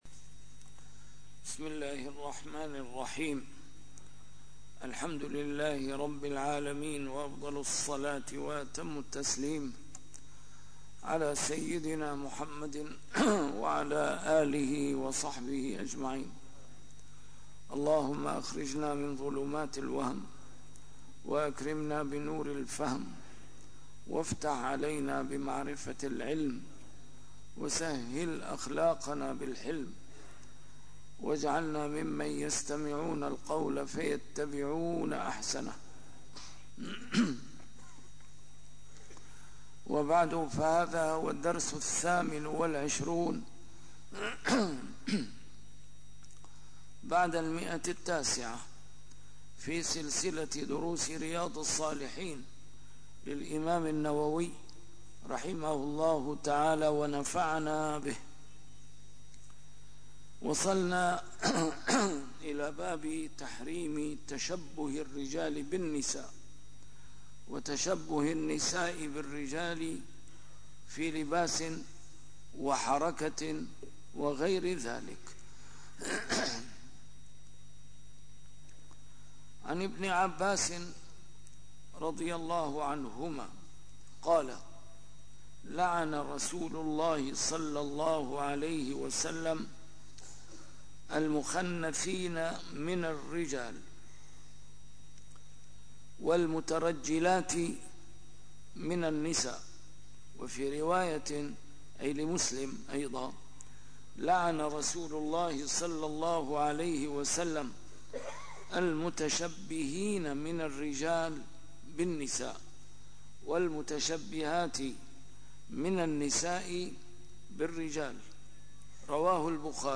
A MARTYR SCHOLAR: IMAM MUHAMMAD SAEED RAMADAN AL-BOUTI - الدروس العلمية - شرح كتاب رياض الصالحين - 928- شرح رياض الصالحين: تحريم تشبه الرجال بالنساء وتشبه النساء بالرجال